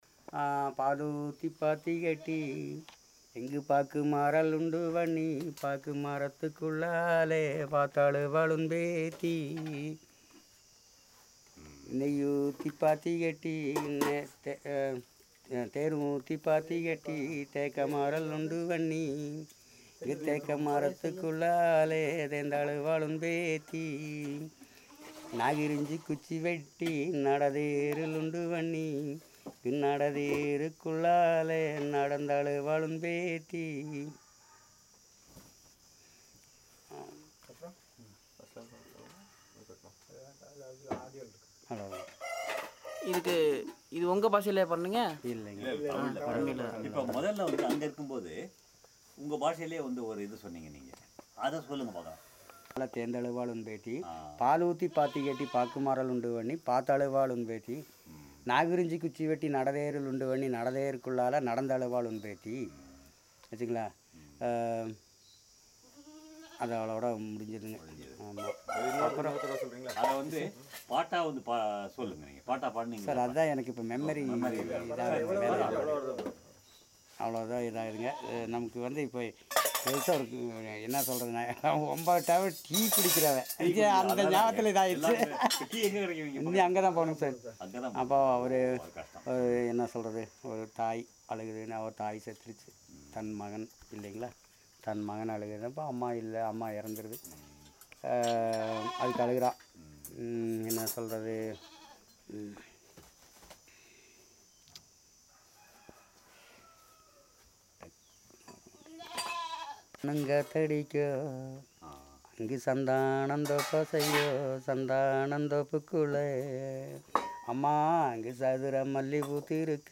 Performance of a mourning song and a lullaby